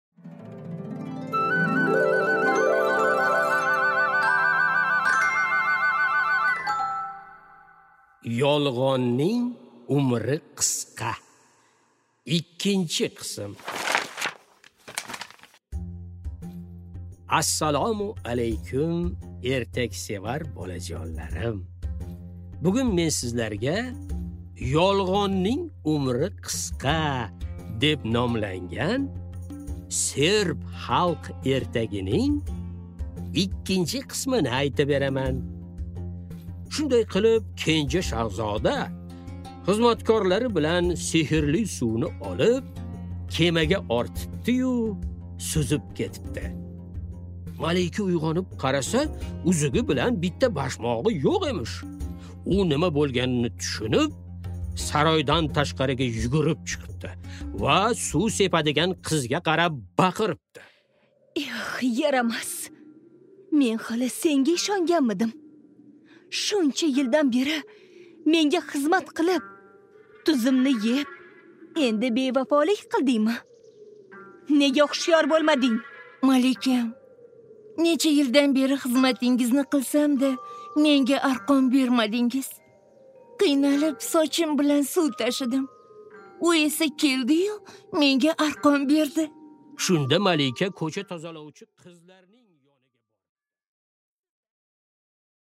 Аудиокнига Yolg'onning umri qisqa 2-qism